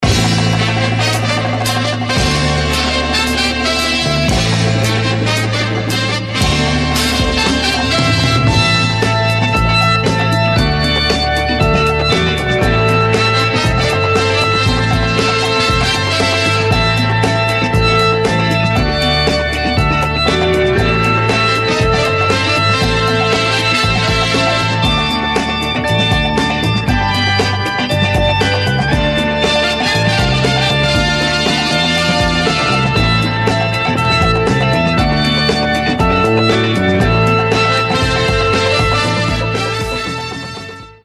• Качество: 320, Stereo
Хип-хоп
без слов
Old school hip hop
Легендарный олдскульный хип-хоп